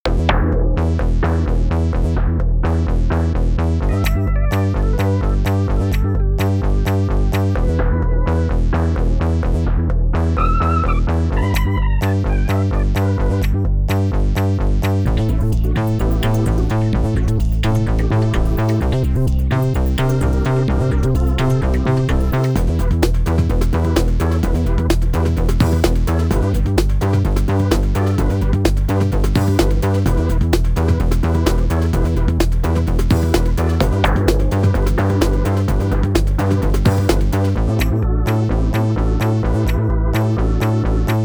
Soul 2 (bucle)
melodía
repetitivo
rítmico
sintetizador
soul